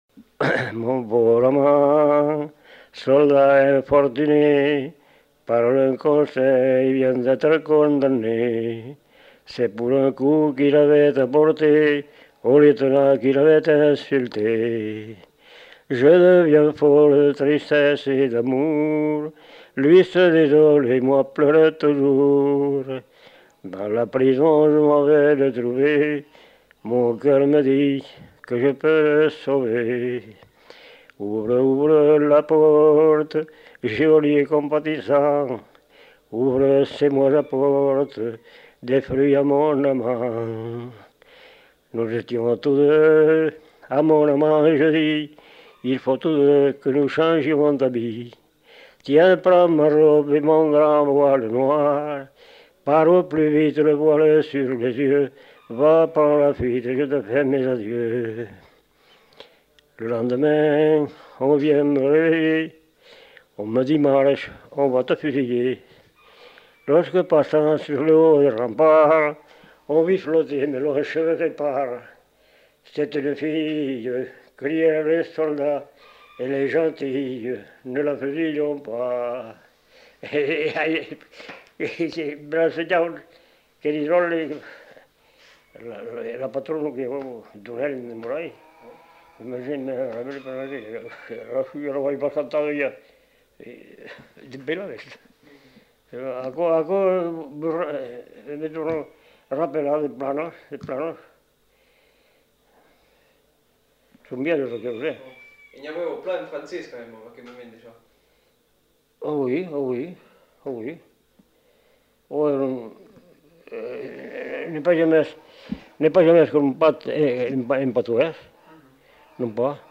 Aire culturelle : Lomagne
Lieu : Faudoas
Genre : chant
Effectif : 1
Type de voix : voix d'homme
Production du son : chanté